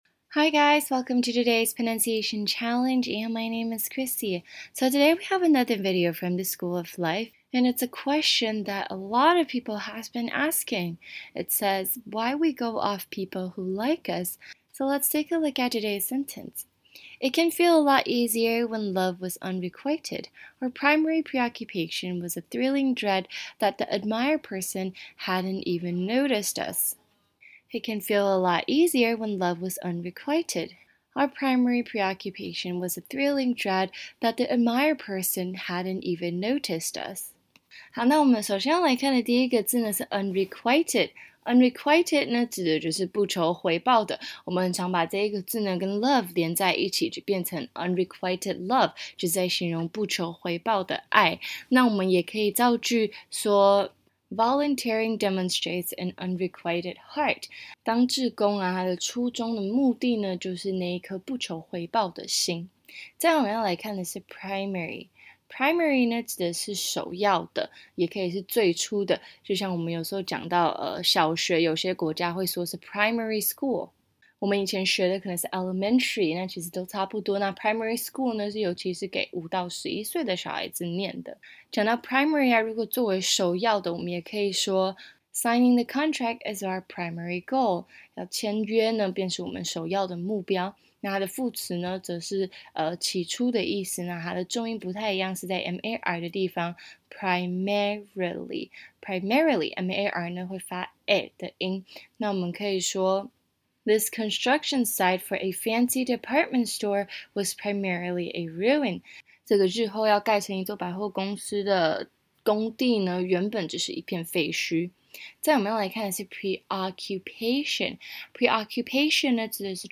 台湾籍旅美老师今天交给我们的句子是：